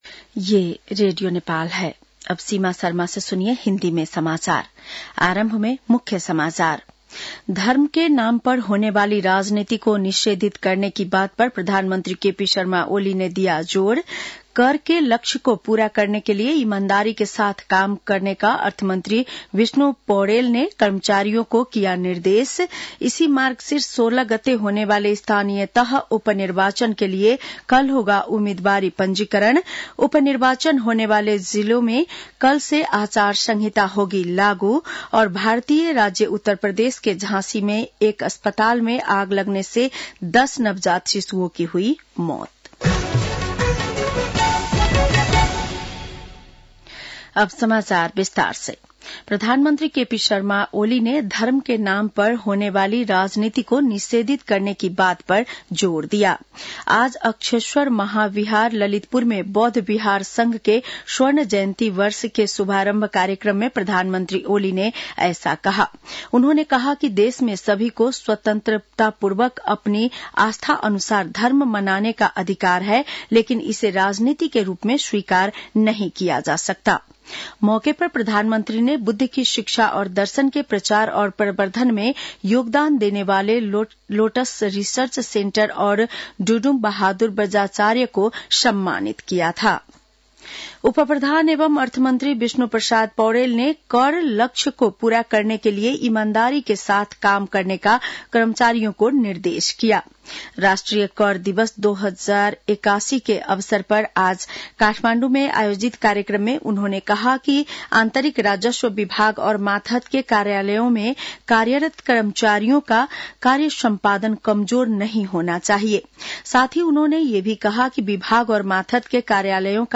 बेलुकी १० बजेको हिन्दी समाचार : २ मंसिर , २०८१